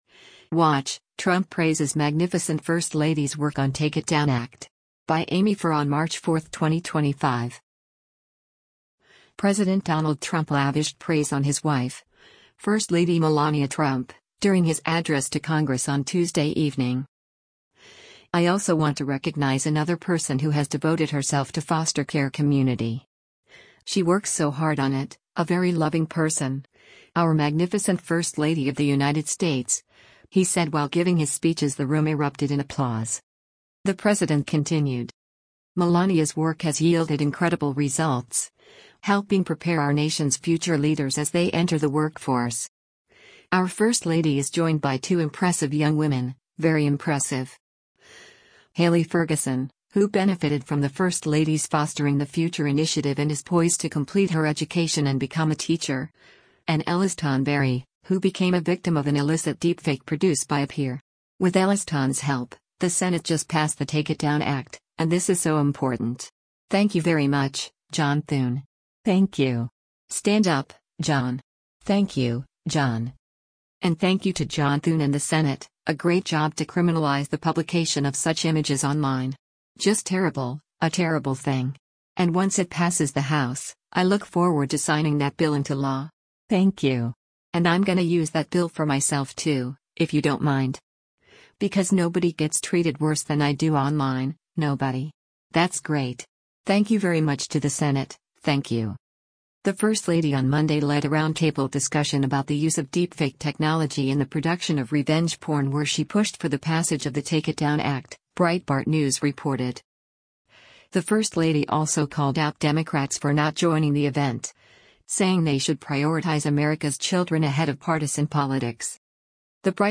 President Donald Trump lavished praise on his wife, first lady Melania Trump, during his address to Congress on Tuesday evening.
“I also want to recognize another person who has devoted herself to foster care community. She works so hard on it, a very loving person, our magnificent first lady of the United States,” he said while giving his speech as the room erupted in applause.